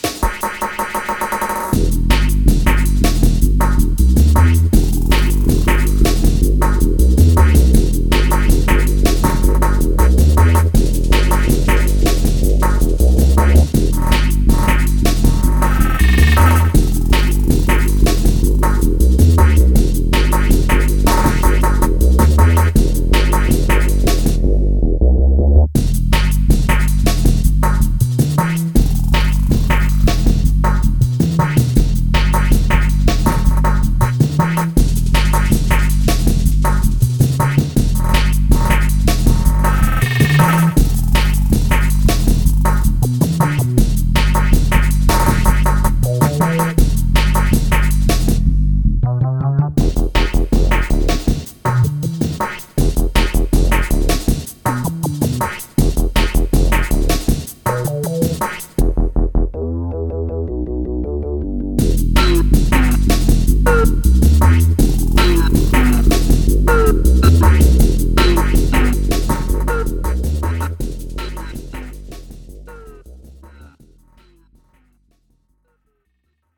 play mp3 clip   atmospheric Drum'n'Bass, earthy bass-line